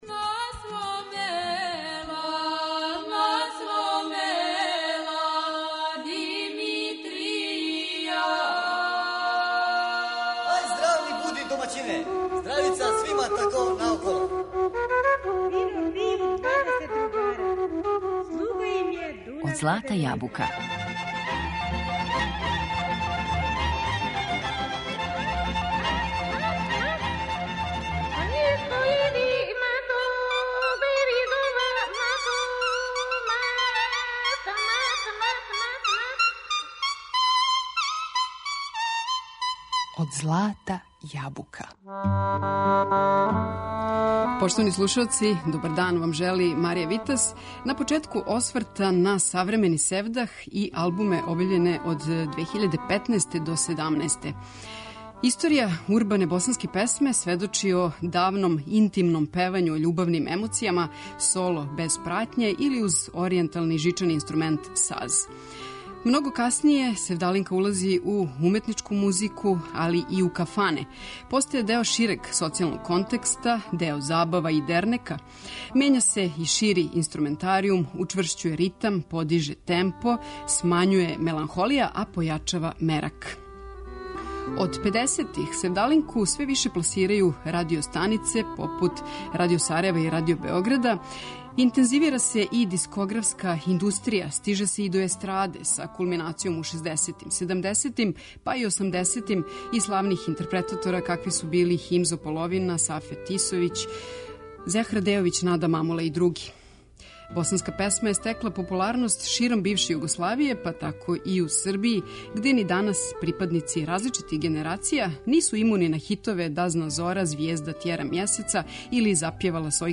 Неосевдах сцена